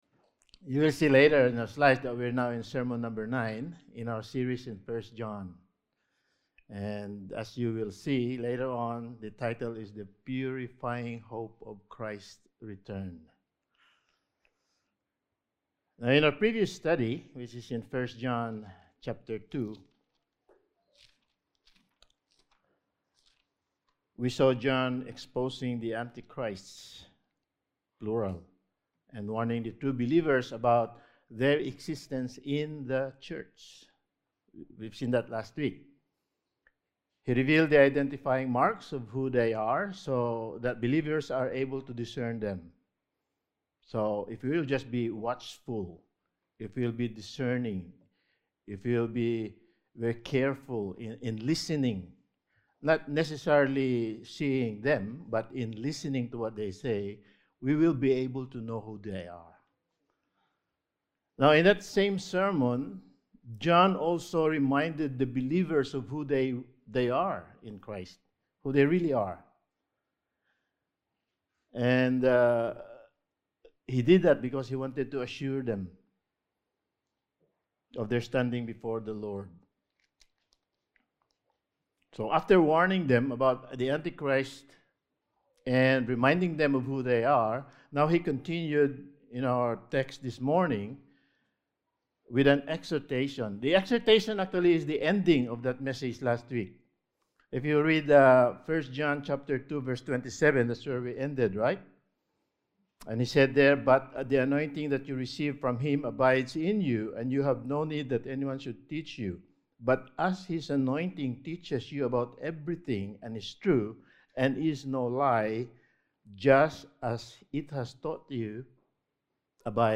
1 John Series – Sermon 9: The Purifying Hope of Christ’s Return
Service Type: Sunday Morning